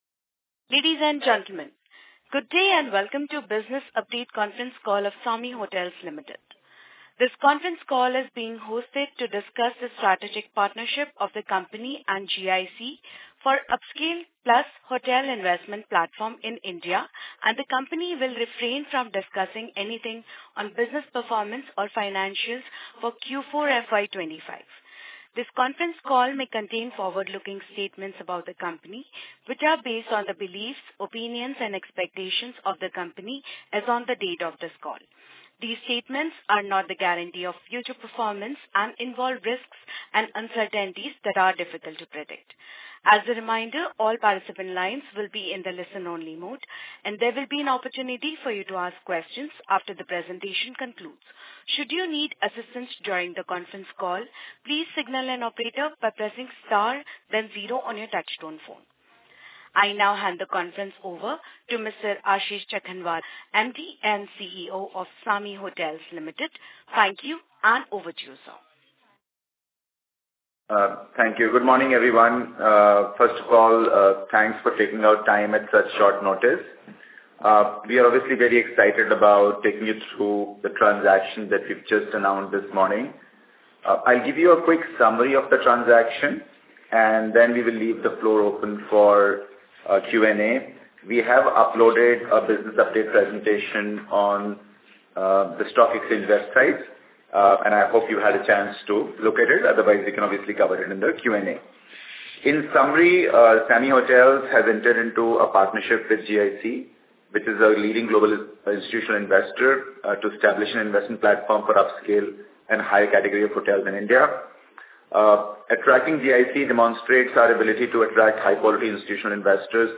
Business-update-call-Audio-Link-GIC-SAMHI-partnership.mp3